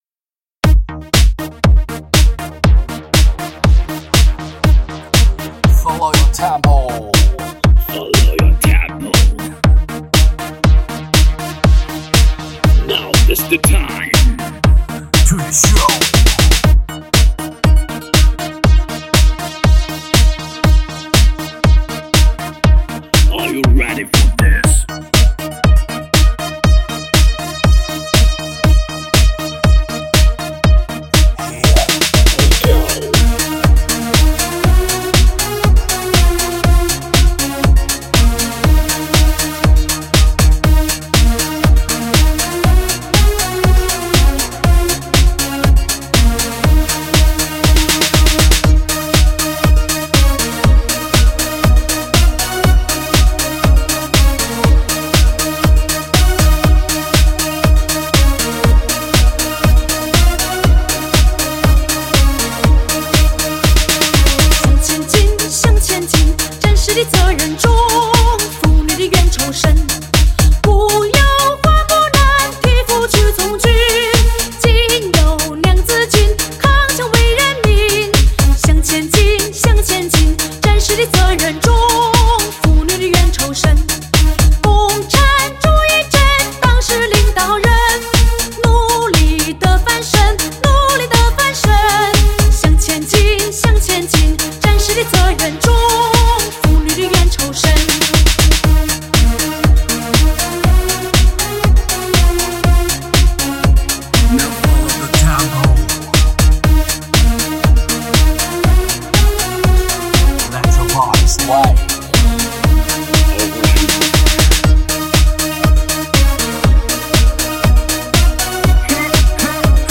收录17首DISCO红歌舞曲  流行唱法  民通唱法  摇滚唱法大胆运用